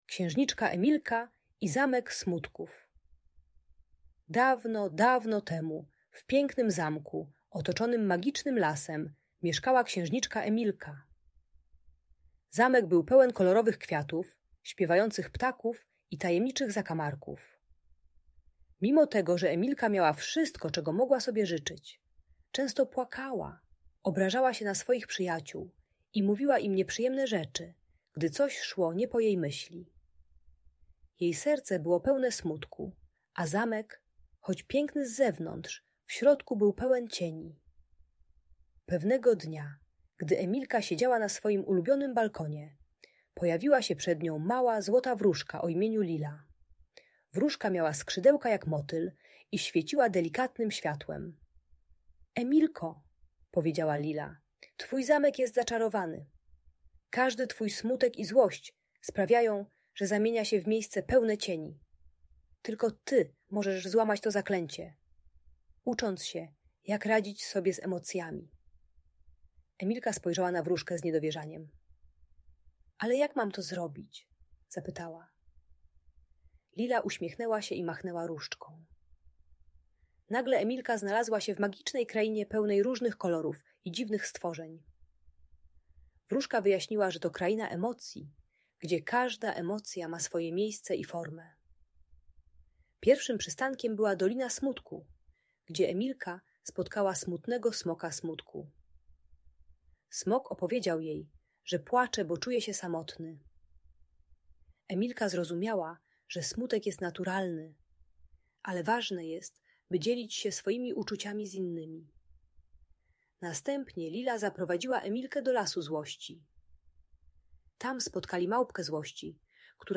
Księżniczka Emilka i Zamek Smutków - Niepokojące zachowania | Audiobajka